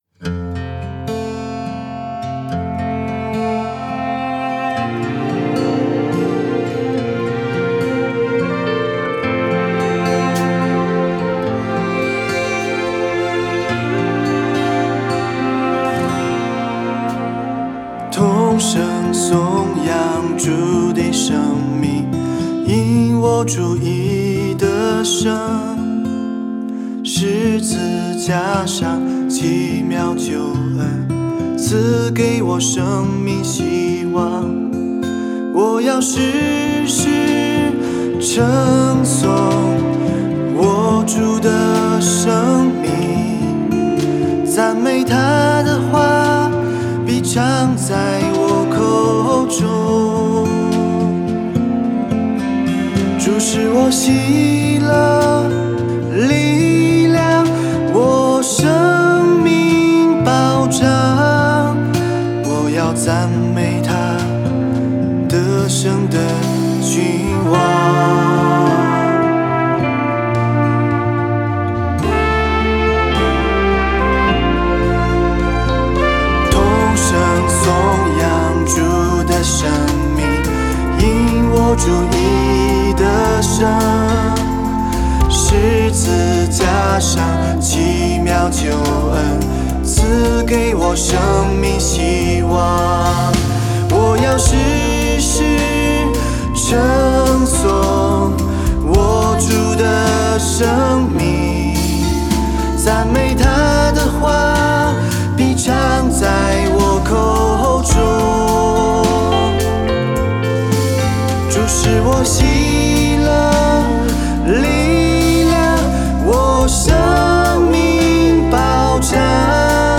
mp3 原唱音樂